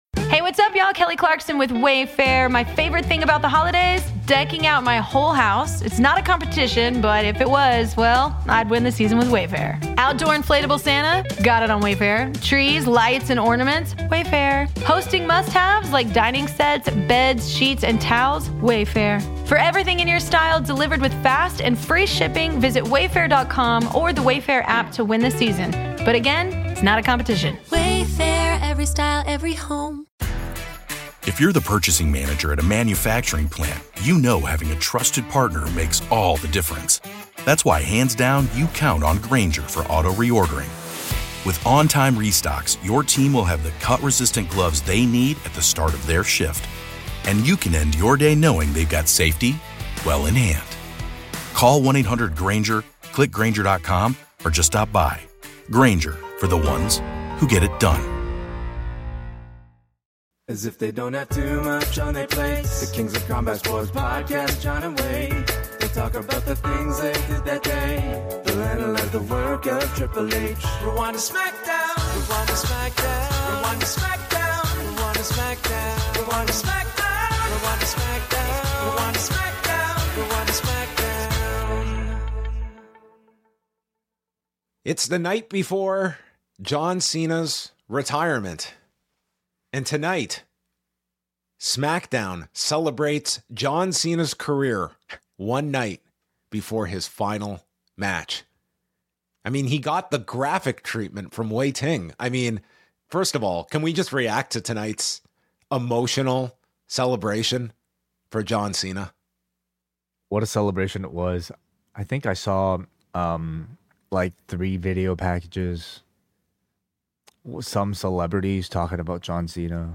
INTERVIEW: Ariel Helwani on the return of The MMA Hour